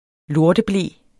Udtale [ ˈloɐ̯də- ]